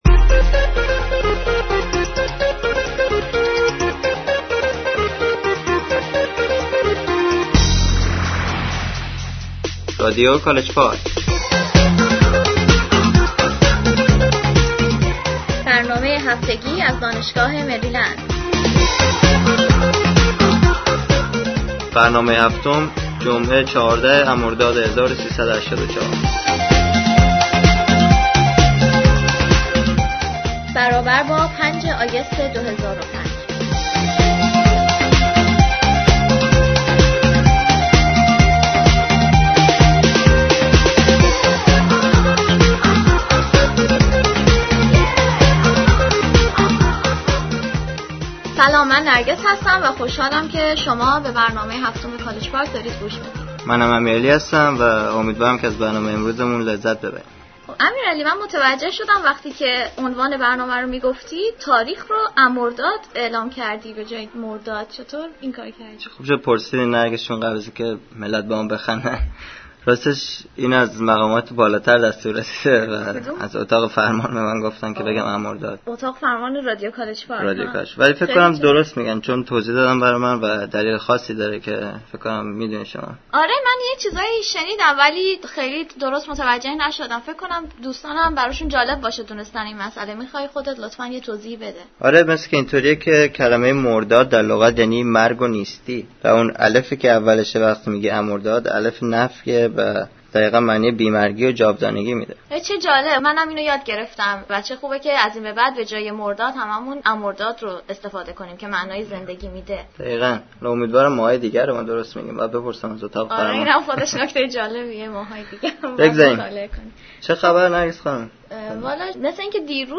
Songs (Comic)